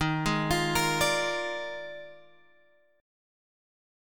D# Major 7th Suspended 2nd Suspended 4th